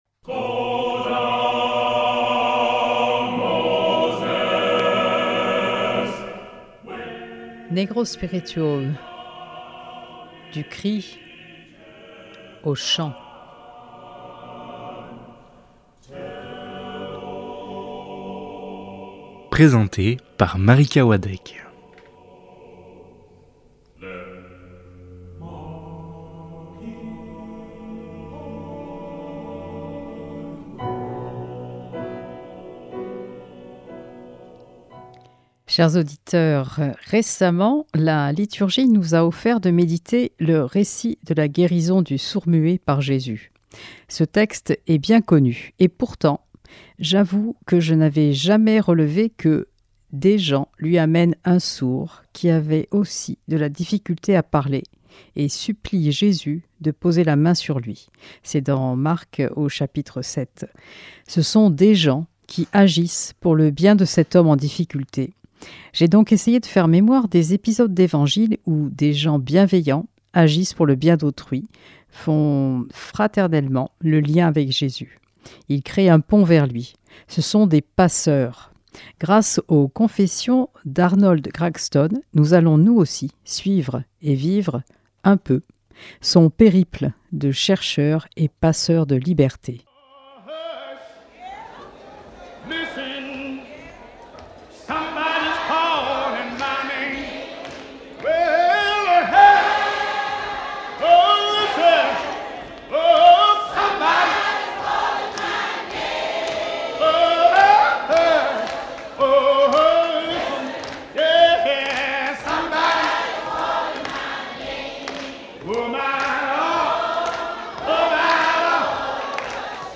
Productrice de Negro spiritual : du cri au chant